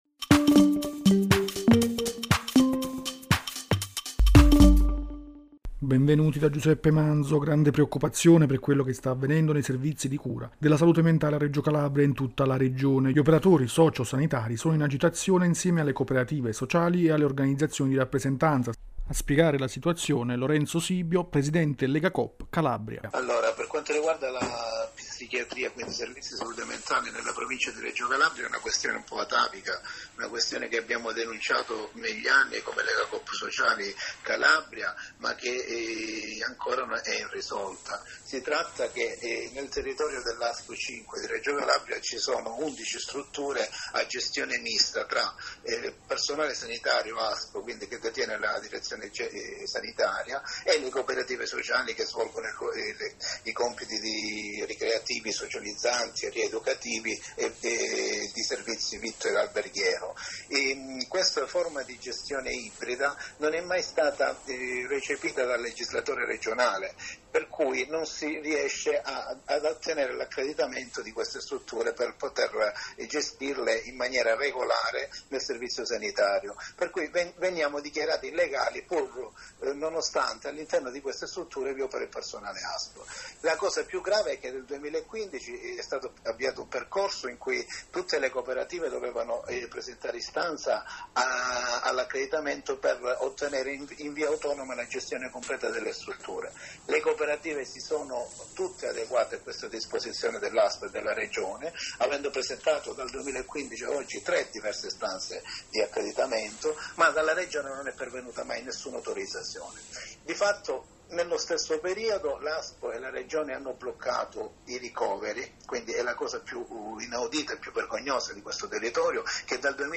Archivi categoria: Intervista